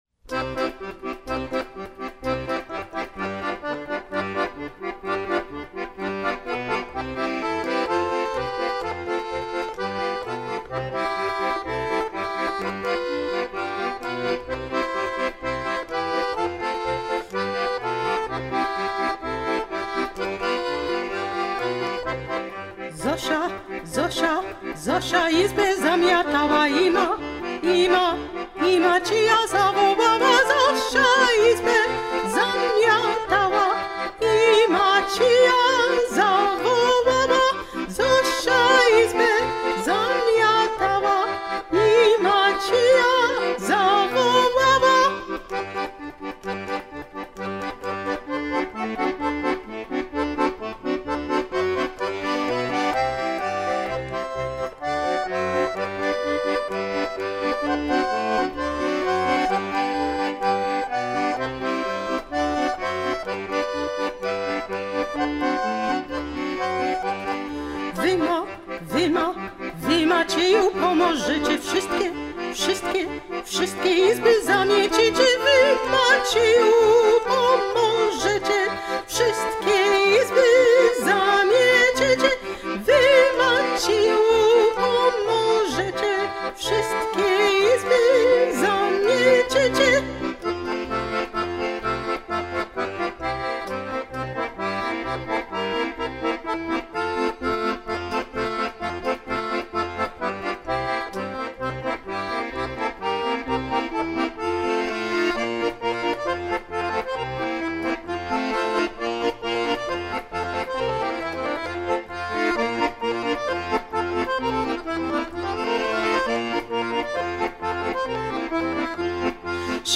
Chicago Accordionist 1
This Chicago Accordionist was born in Chicago and, since she was 14, has been researching, performing and preserving Slavic folk music.
In addition to singing in over a dozen languages, she is proficient in accordion, which is her main instrument.